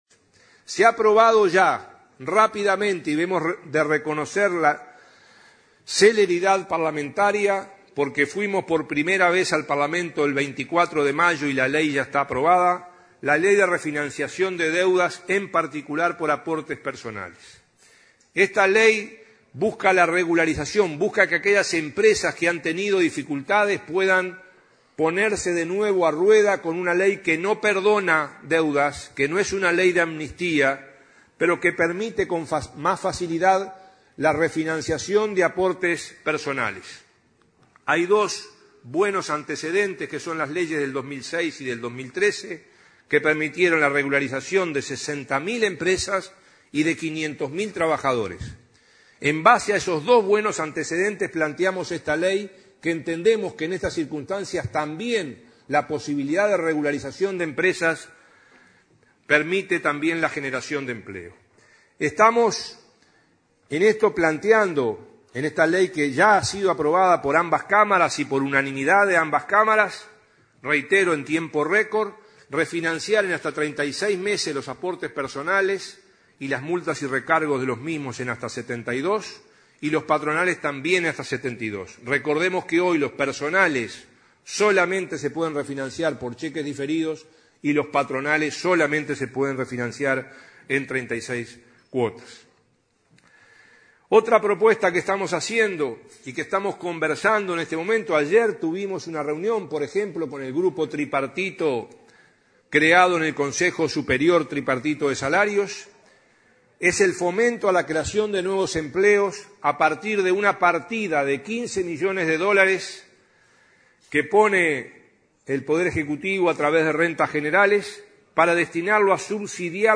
El ministro de Trabajo, Ernesto Murro, destacó, durante su disertación en ADM, la aprobación de la ley de refinanciación de deudas de aportes personales.